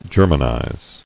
[Ger·man·ize]